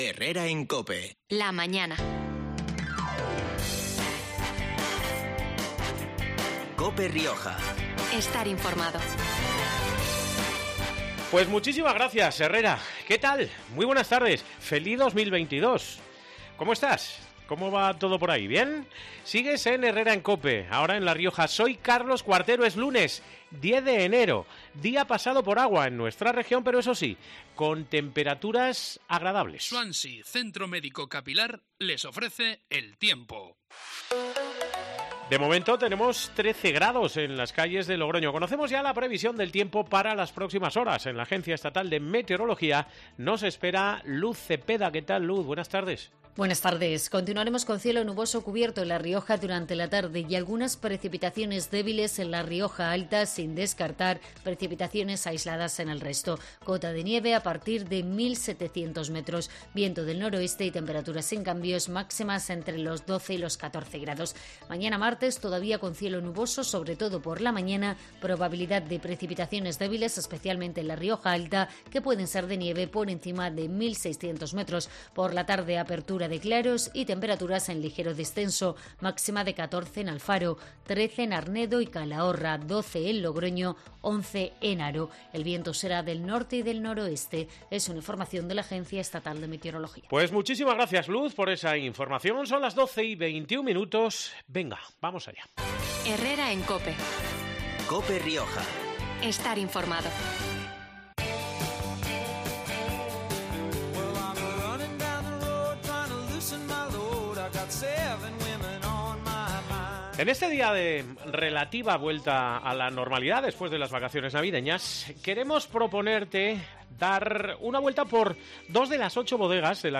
representadas hoy, a través del teléfono,